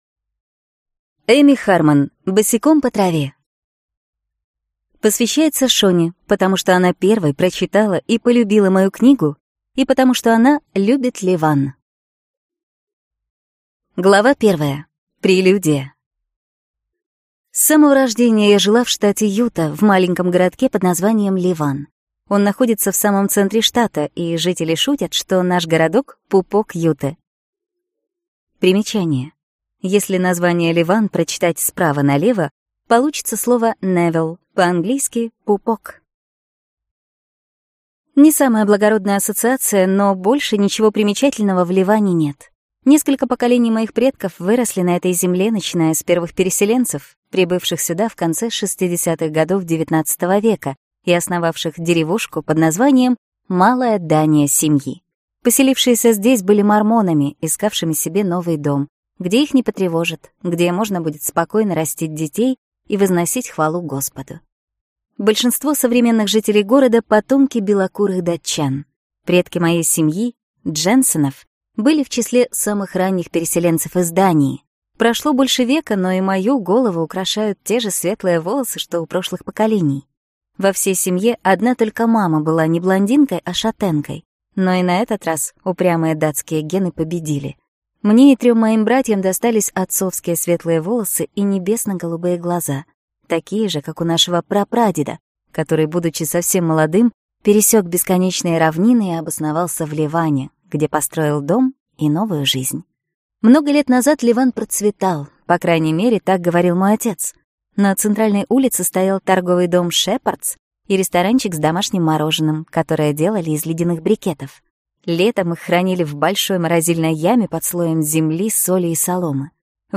Аудиокнига Босиком по траве | Библиотека аудиокниг